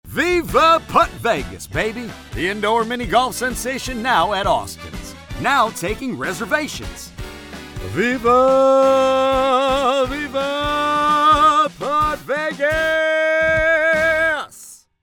Male
Singing
Elvis Voice - Viva Putt Vegas